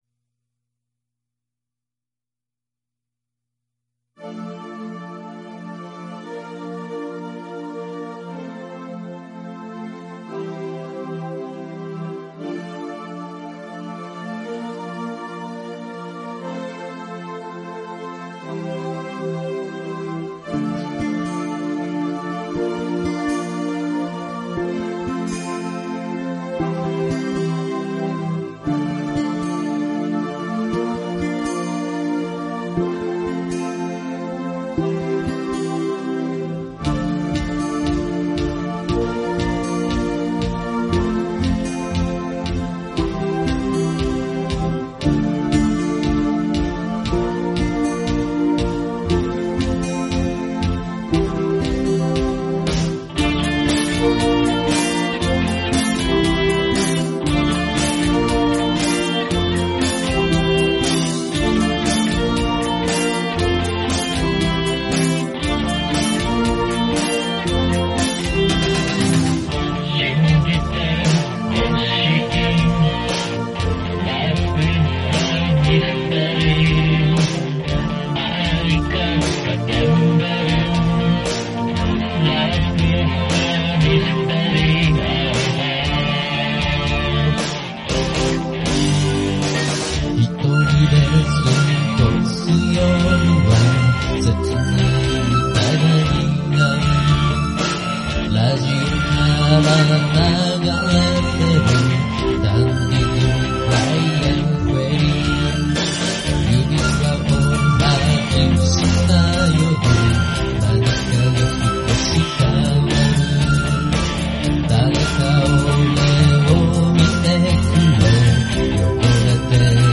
Vocal,A.guitar,E.guitar,Bass,Keyboard,Drums
Chorus
John Wetton時代のエイジア風サウンドです。